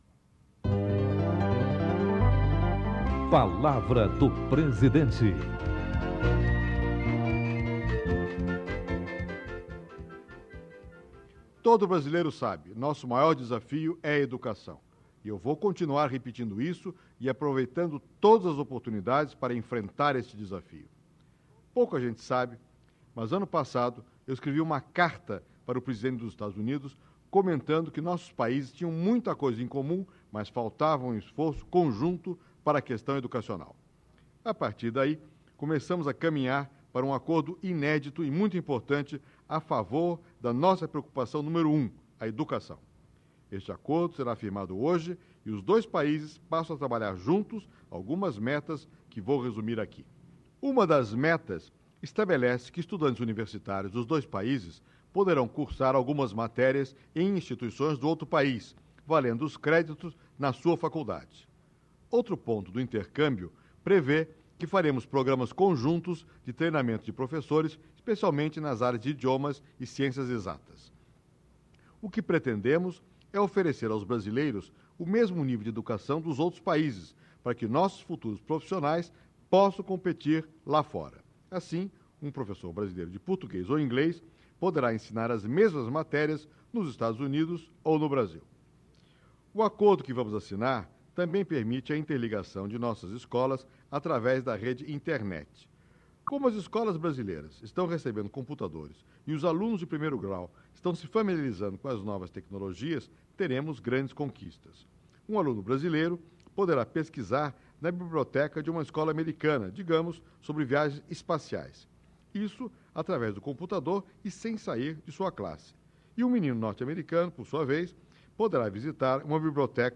Em 2007, o presidente Fernando Henrique Cardoso apresenta resultados da iniciativa no programa Palavra do Presidente:
Radiojornal: Programa Palavra do Presidente, Brasília (DF) , 10/10/1997 (Acervo Pres. F. H. Cardoso)/ Produtor: Radiobrás – Empresa Brasileira de Comunicação.